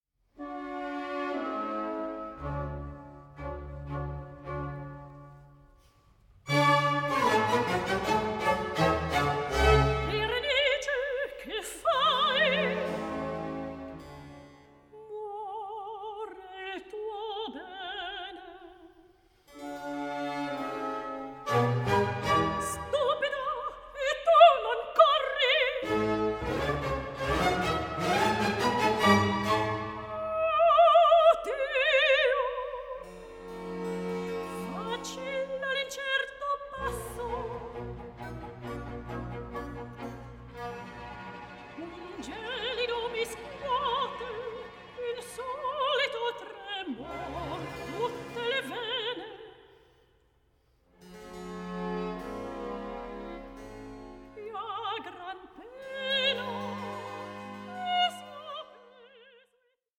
PASSIONATE VOCAL AND SYMPHONIC MUSIC FROM THE CLASSICAL ERA
period-instruments ensembles